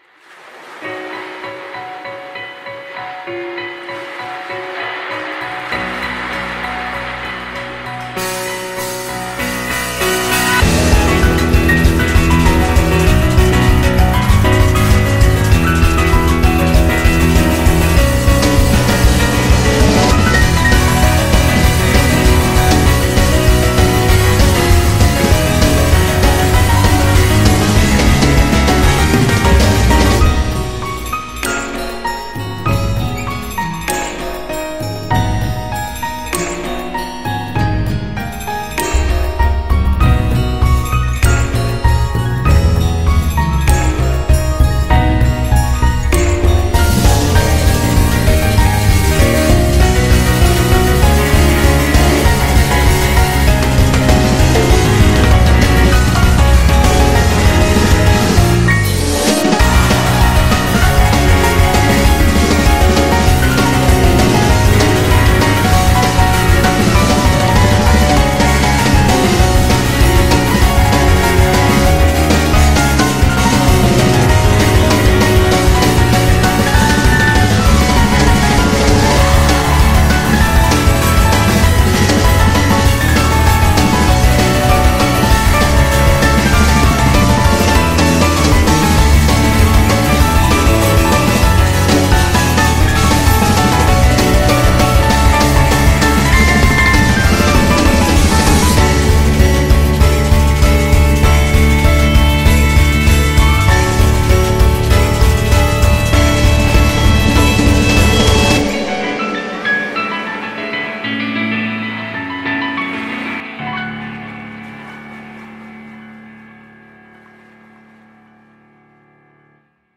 BPM94-196
Audio QualityPerfect (Low Quality)